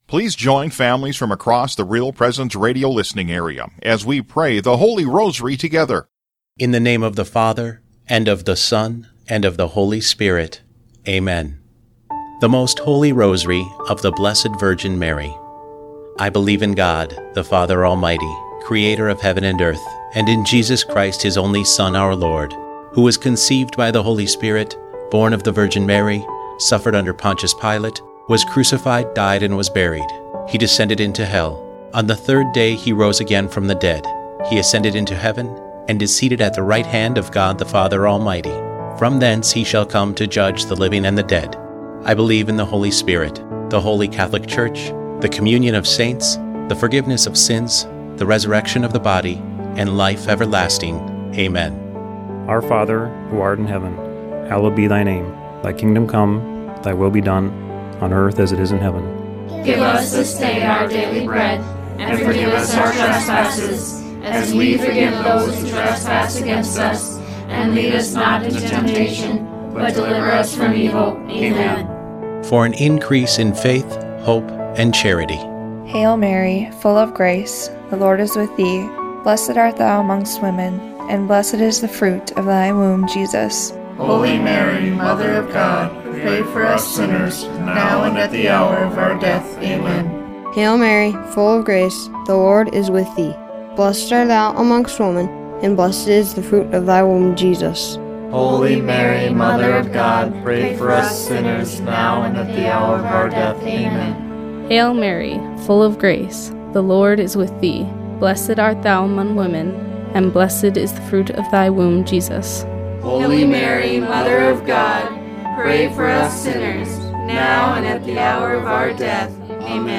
We invite you to pray our special presentation of the Glorious Mysteries of the RPR Family Rosary. We visited families throughout our listening area and recorded this beautiful prayer with moms, dads, and their children.